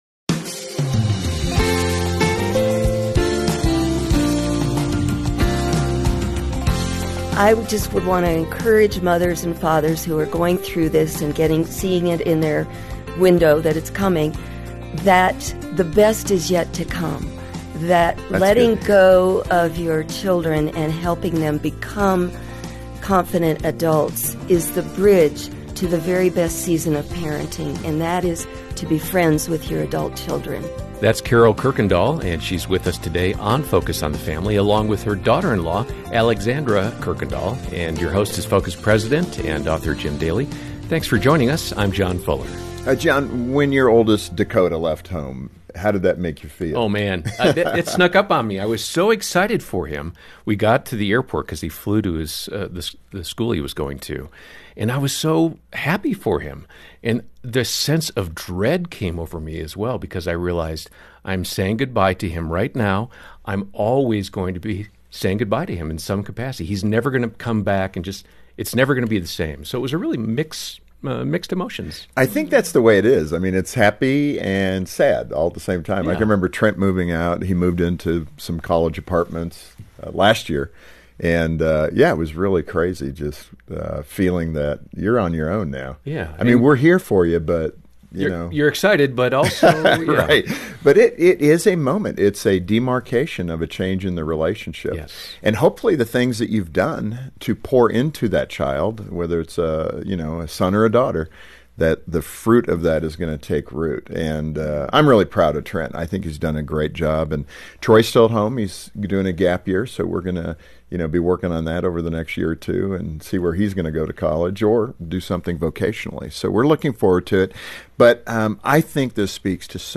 Focus on the Family Daily Setting Your Teen Free to Soar Play episode August 20 25 mins Bookmarks Episode Description Even though you know the day is coming when your child will leave home, there’s a whirlwind of emotion that comes with it. Two moms explore those feelings with personal stories and some practical tips.